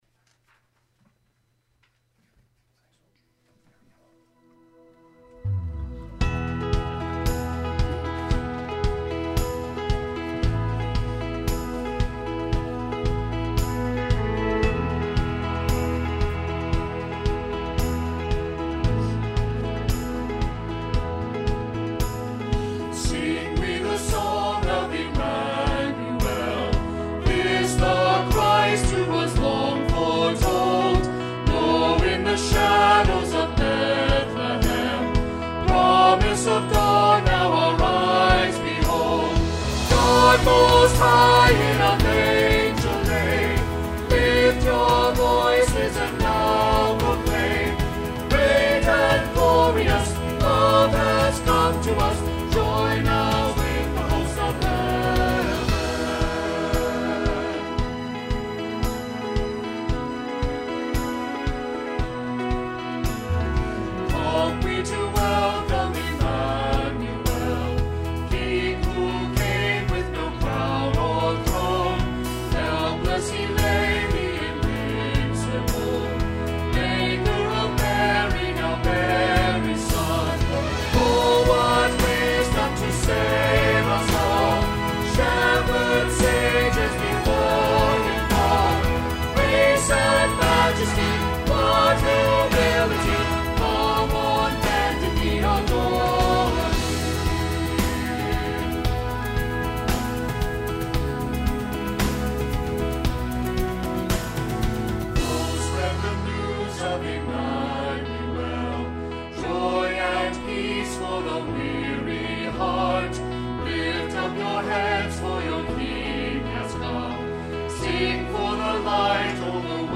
Music – Sing We the Song of Emmanuel (Christmas Cantata, Part 3, 12/20/20)